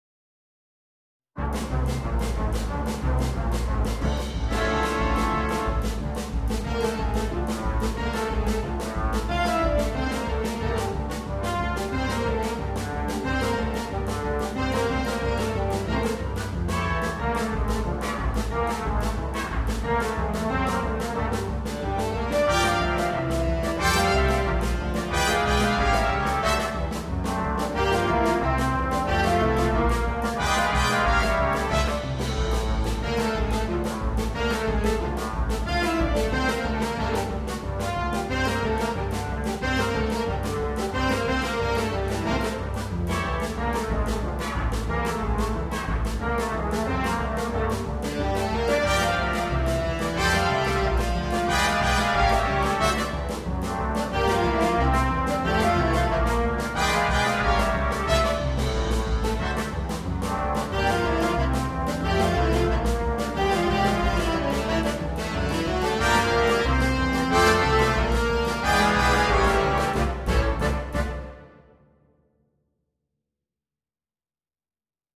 для биг-бэнда.